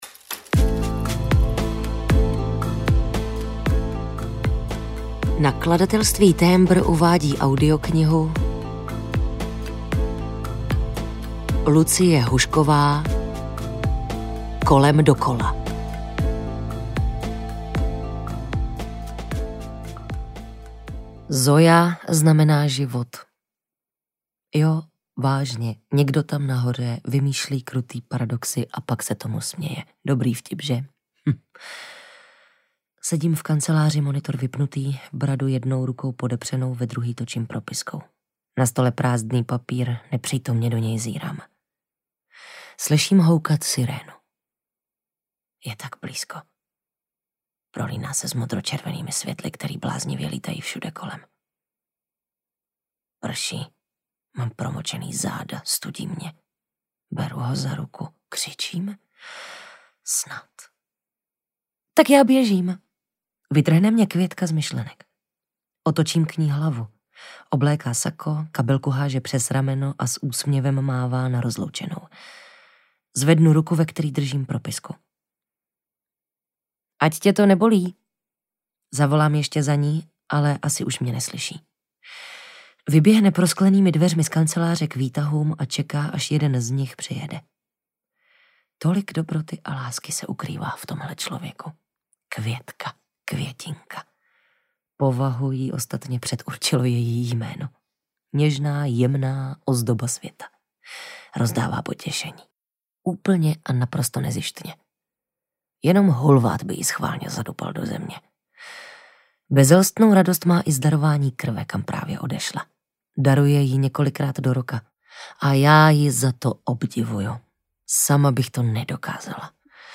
Kolem dokola audiokniha
Ukázka z knihy